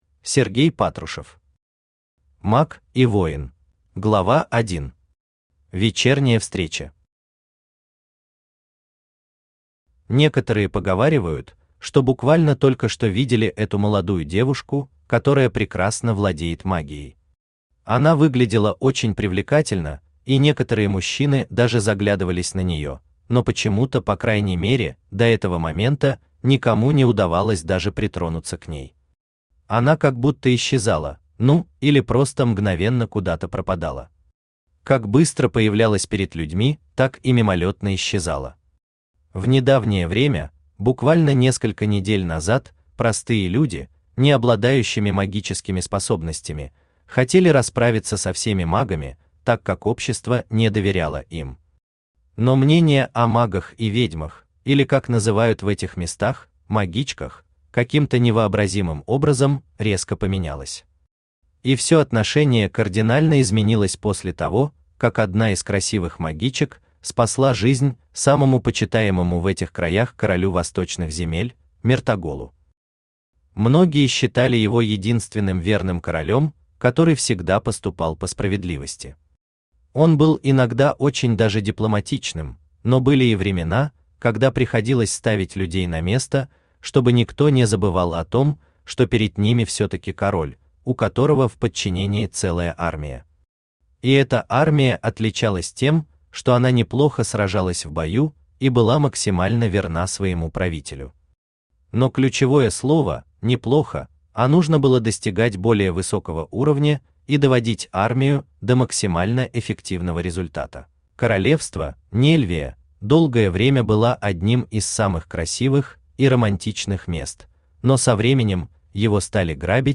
Aудиокнига Маг и воин Автор Сергей Патрушев Читает аудиокнигу Авточтец ЛитРес.